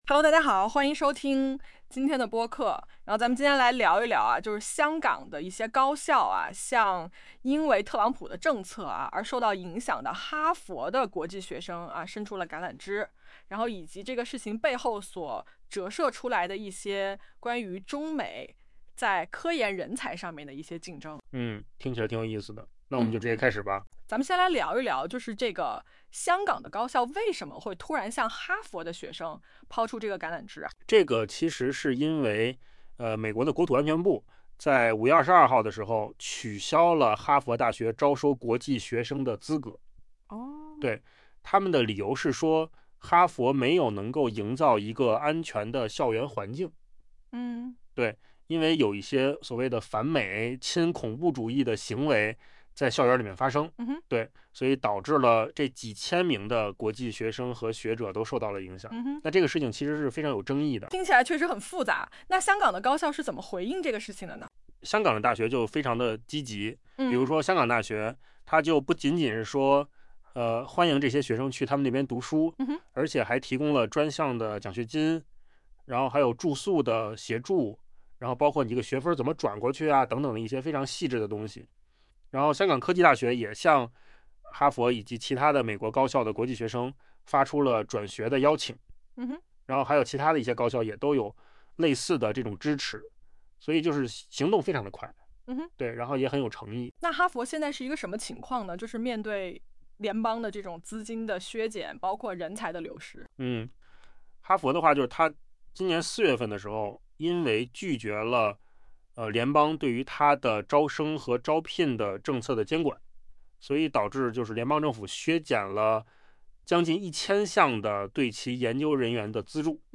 不得不赞叹，扣子空间生成的播客和真人录制的语音效果几乎没有区别了，AI免费打工的图景正成为现实。